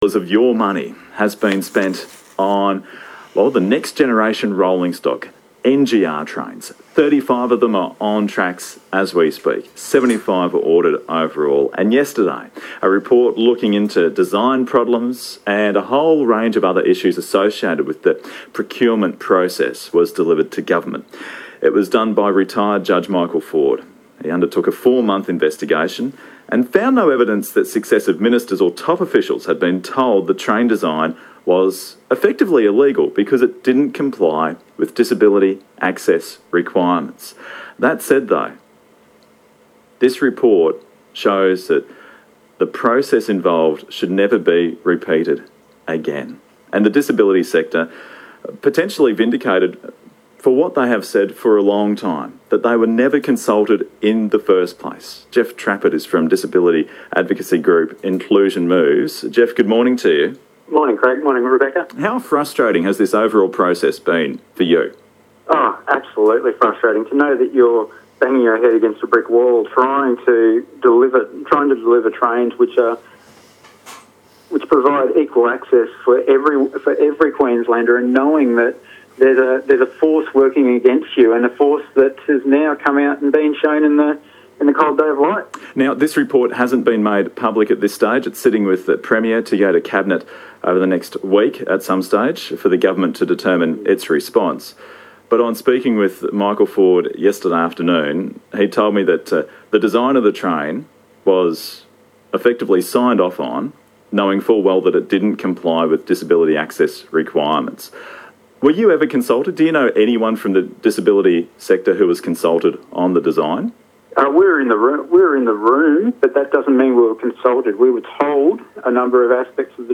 Interview on ABC Radio Brisbane Breakfast